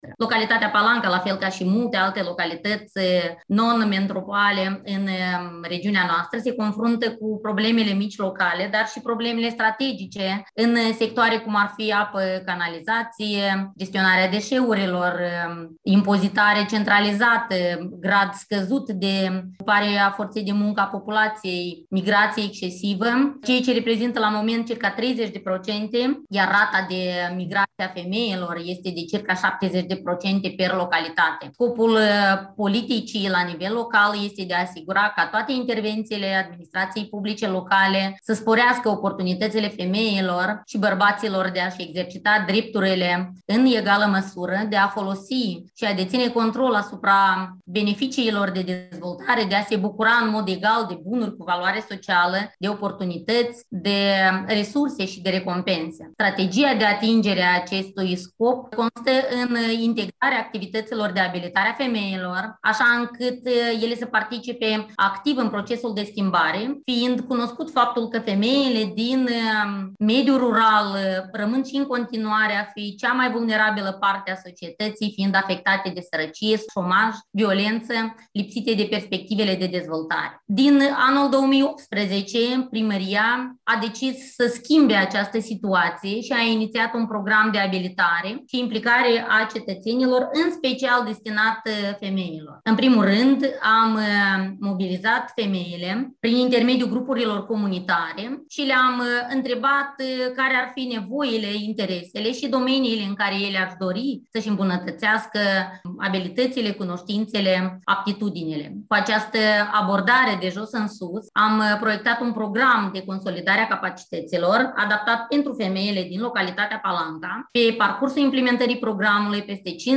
La cel de-al doilea Forum al femeilor primar din Europa de Sud-Est, organizat cu sprijinul UN Women în cadrul Adunării Generale a NALAS, Larisa Voloh, primarul satului Palanca, Ștefan Vodă, vicepreședinte al CALM, președintele Rețelei Femeilor Primar din cadrul CALM, a vorbit despre provocările cauzate de pandemie și a prezentat Programul de abilitare economică a femeilor, lansat în localitatea sa în 2018.